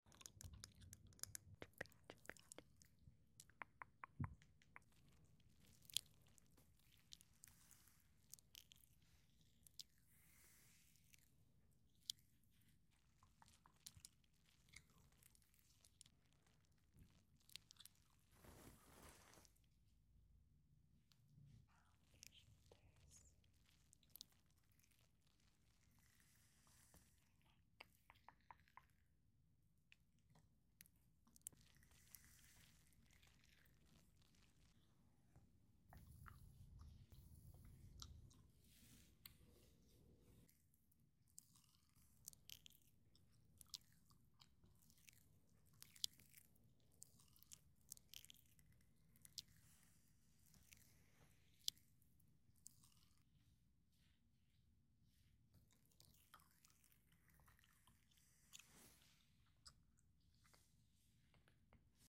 ASMR candle back and nape sound effects free download